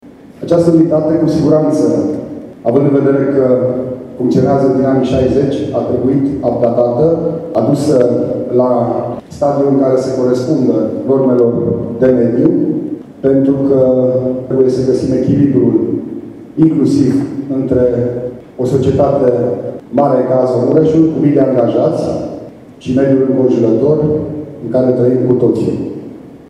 Prefectul județului Mureș, Lucian Goga, și-a exprimat speranța că noile investiții vor reduce disconfortul creat de poluare.